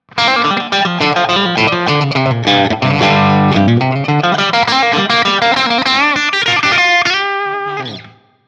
Riff
I recorded this guitar using my Axe-FX II XL+, direct into my Macbook Pro using Audacity.
Since there is only one pickup and thus no pickup selector switch, the recordings are each of the one pickup with the knobs on 10.